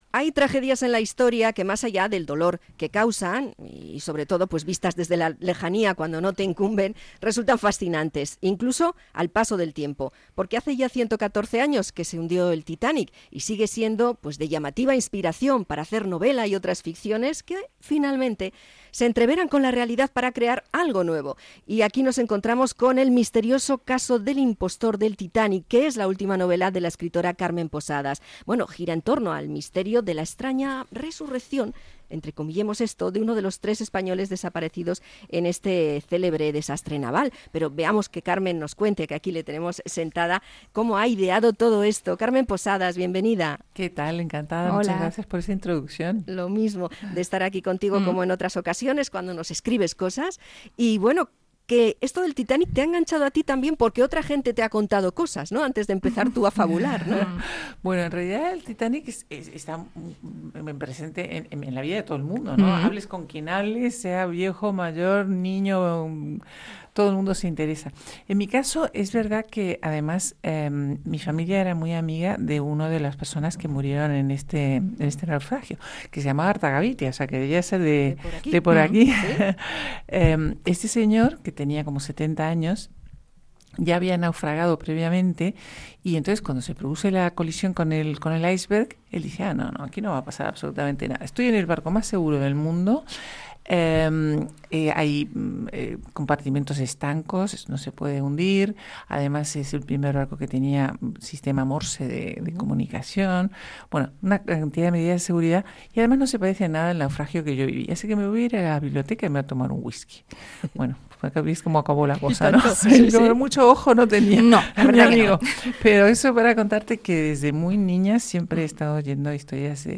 Entrevista a Carmen Posadas por su libro "El misterioso caso del impostor del Titanic"